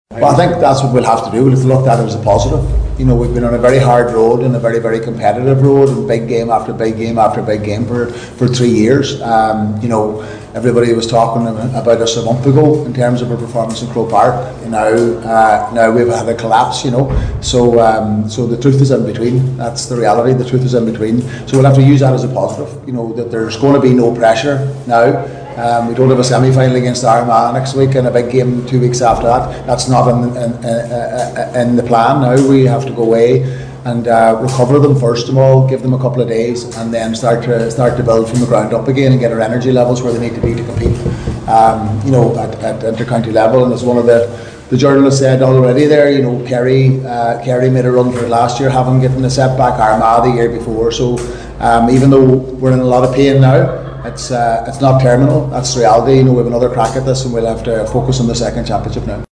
Donegal boss Jim McGuinness says they need to regroup and get the energy levels back up for the next championship: